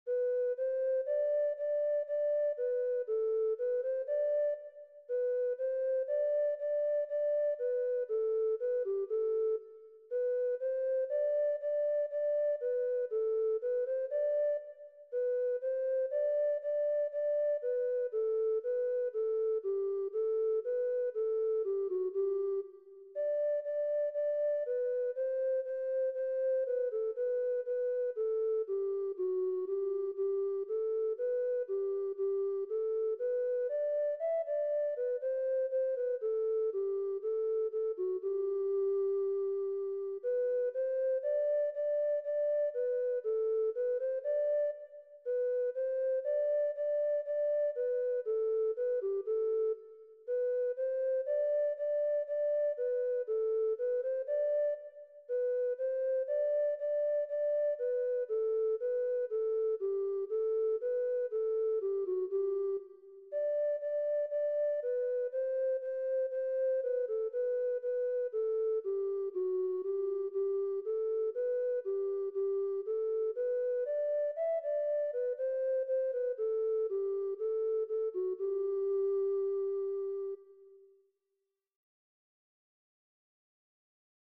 Compositeur : Anonyme (d�but XIII si�cle) Chant Traditionnel FAILED (the browser should render some flash content here, not this text).